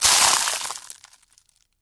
Звуки вафель
Звук раскрывающихся вафель и рассыпающихся крошек